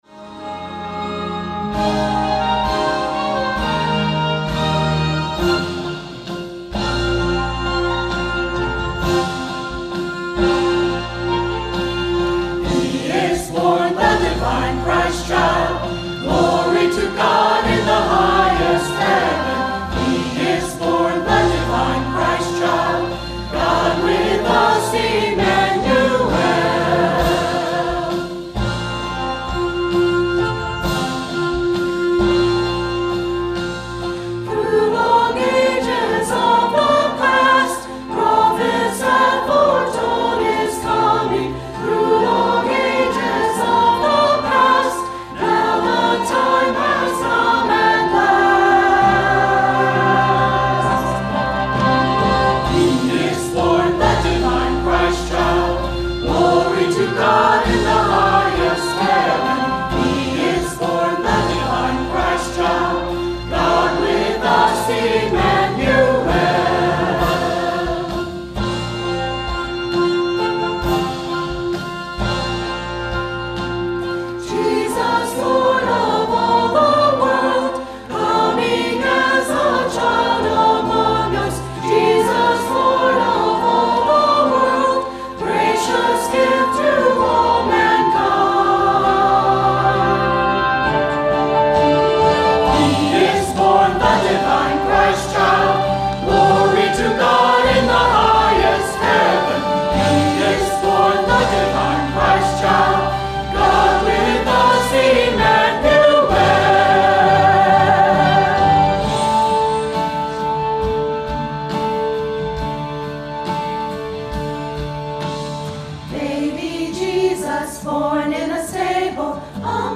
Christmas Cantata 2021 - One Small Child | LibertyBaptistChurch
Service Audio